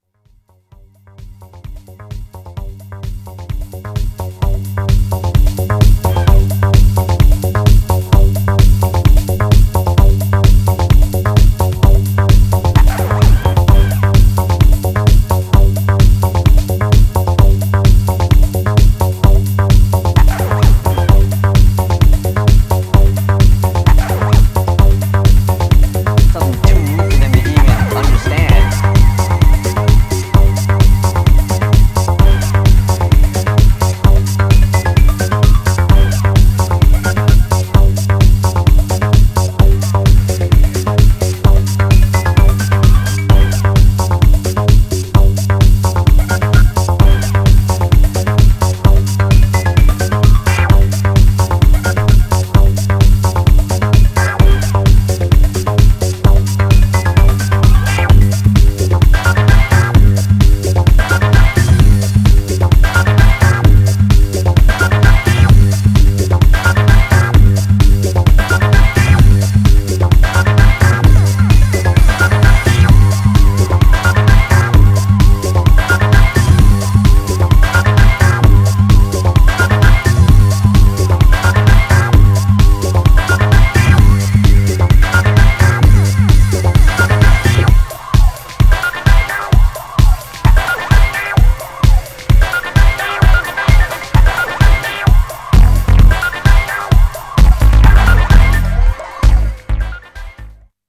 Styl: House, Breaks/Breakbeat